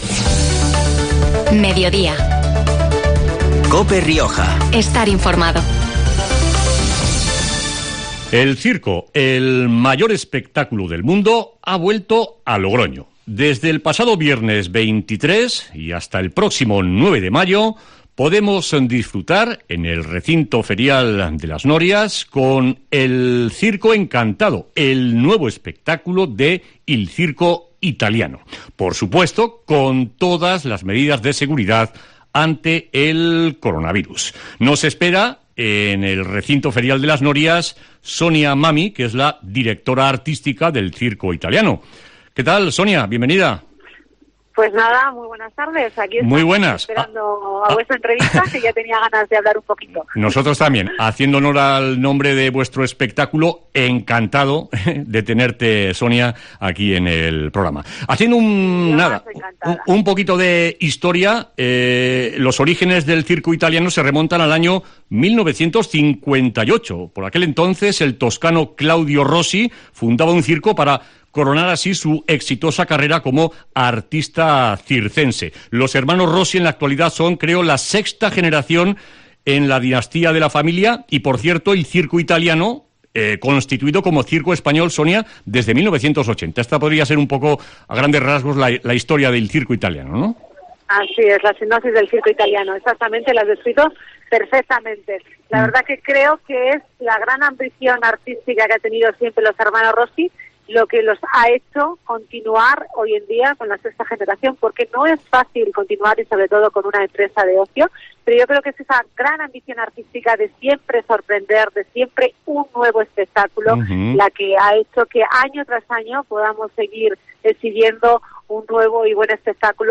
Entrevista en COPE Rioja